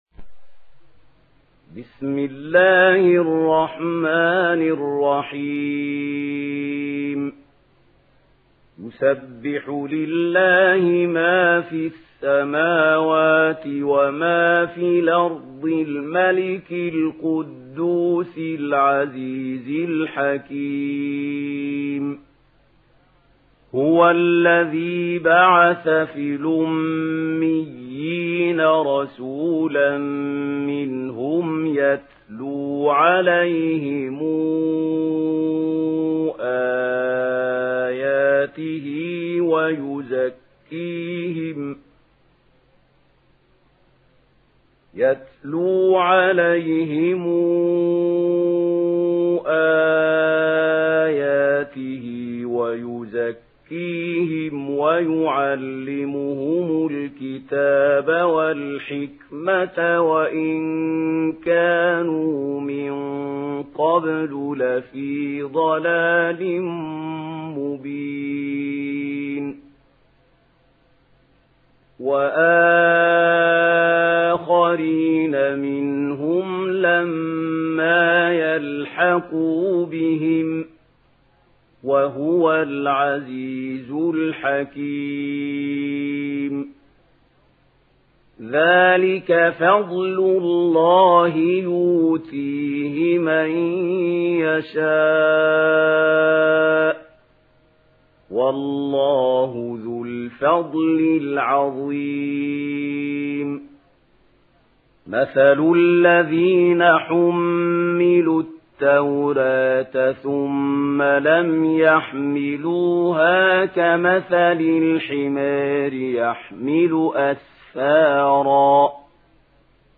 Surah الجمعه MP3 by محمود خليل الحصري in ورش عن نافع narration.
مرتل